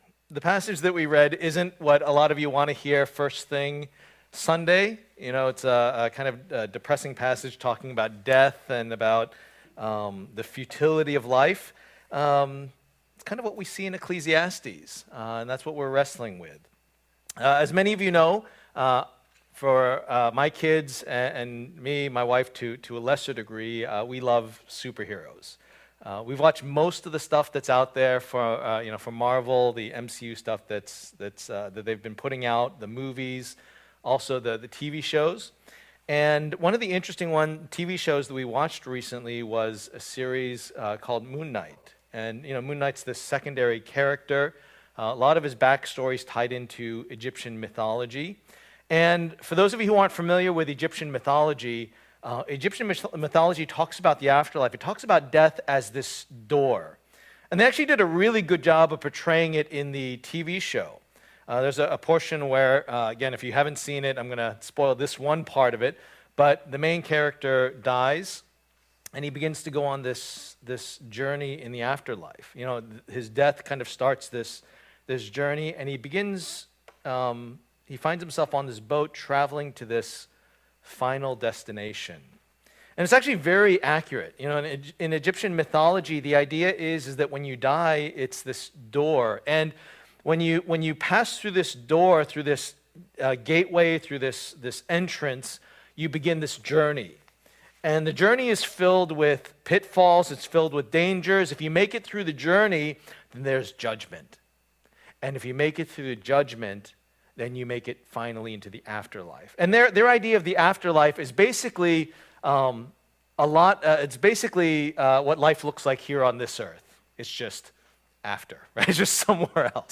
Series: Searching for Meaning: A Study of Ecclesiastes Passage: Ecclesiastes 9:1-11 Service Type: Lord's Day